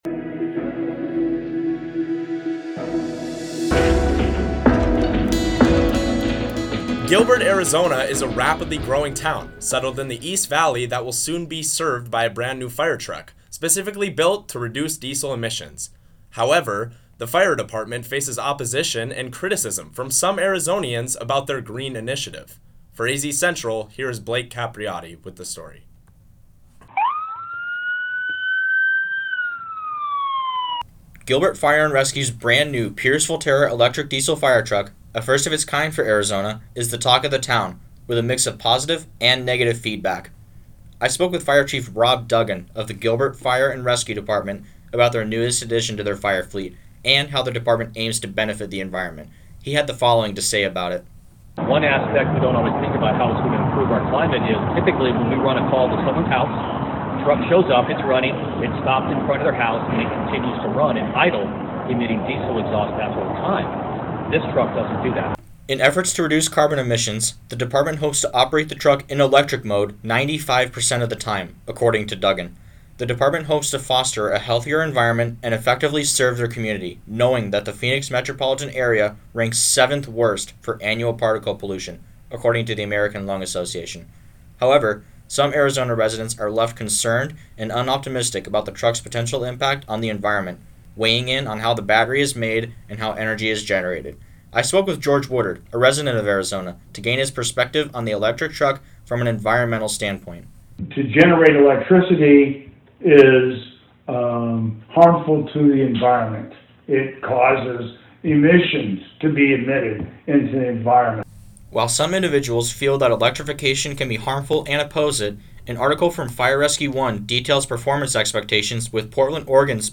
Radio News Story - Gilbert Fire & Rescue Receives First Electric-Powered Fire Truck in Arizona